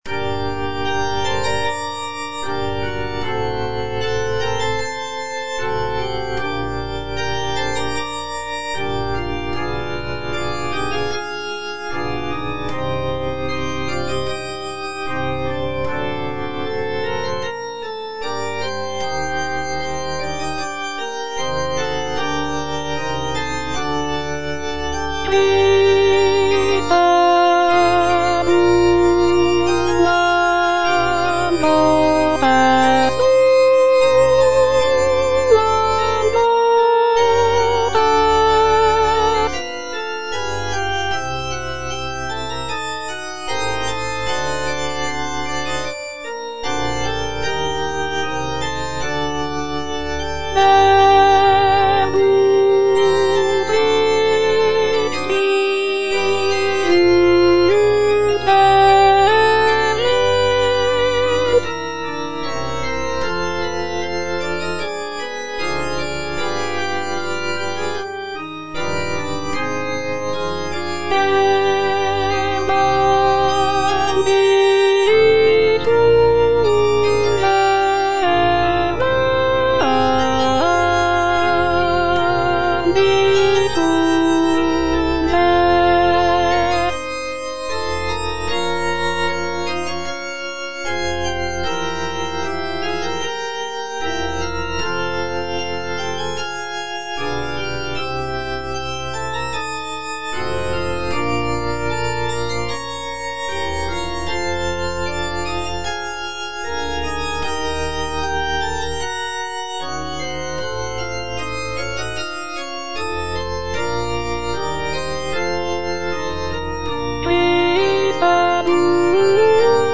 Cantata
Alto (Voice with metronome) Ads stop